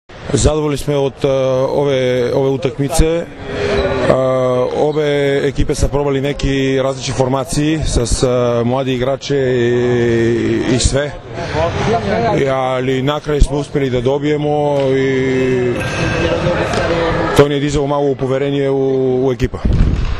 IZJAVA VLADIMIRA NIKOLOVA